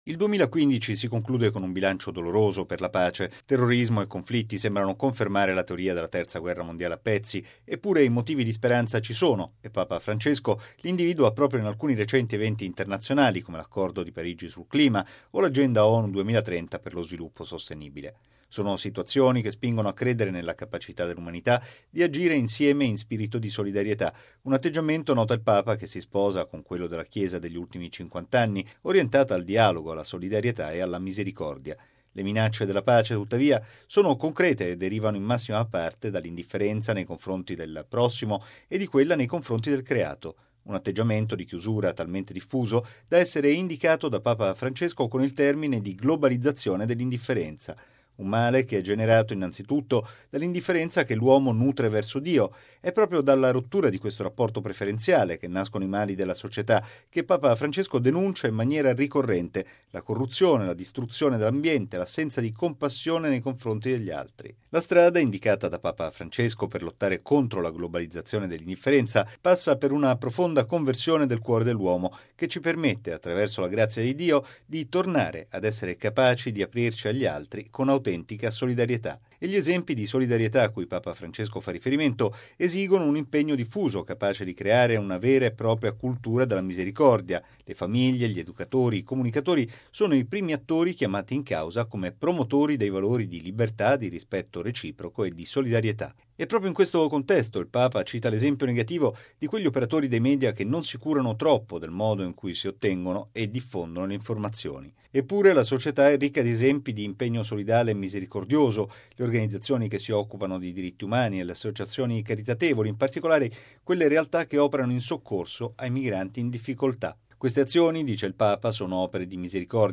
BOLLETTINO RADIOGIORNALE del 15/12/2015